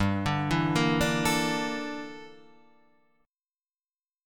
G Minor 6th Add 9th
Gm6add9 chord {3 5 2 3 3 5} chord